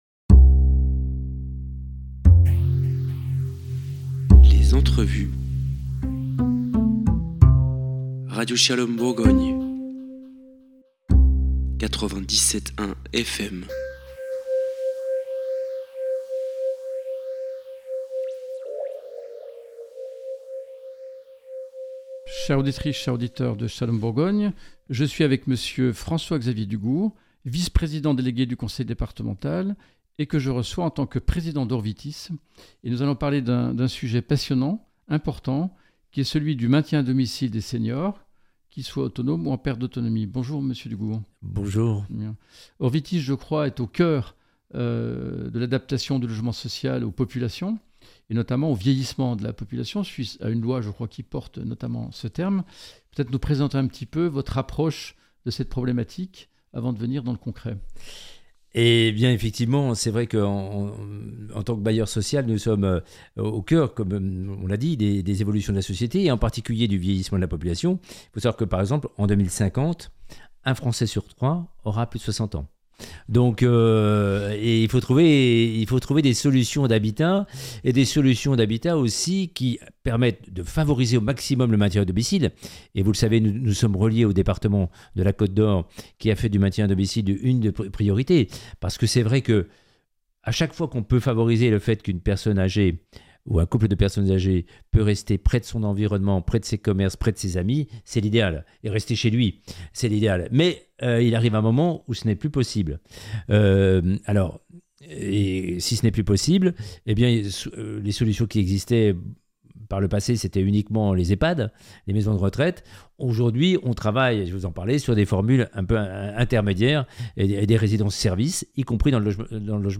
05 août 2025 Écouter le podcast Télécharger le podcast « Shalom Bourgogne » reçoit François-Xavier DUGOURD, vice-président délégué du Conseil Départemental et président d’Orvitis pour nous présenter la politique de l’office public de l’habitat de la Côte-d’Or en matière de maintien à domicile des personnes âgées. Orvitis a développé des résidences « Sérénitis » dont le concept pourrait devenir un label au niveau du territoire national.
Entretien